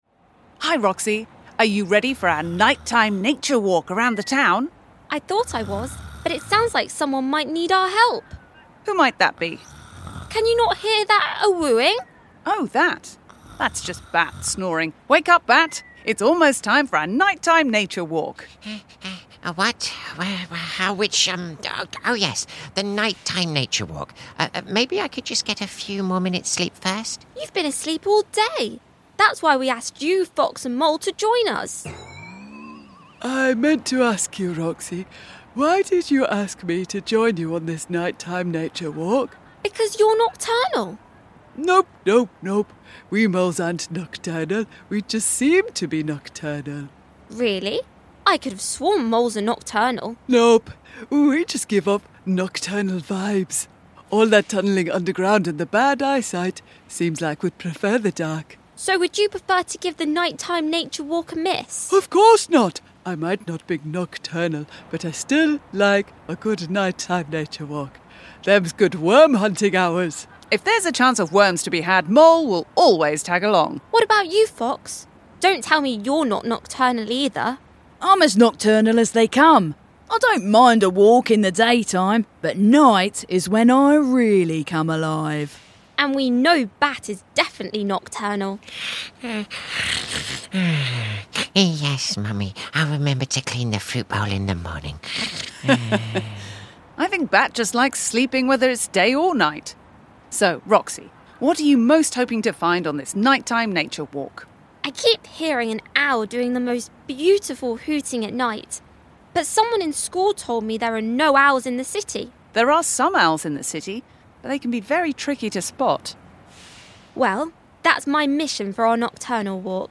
All other characters played by members of the ensemble.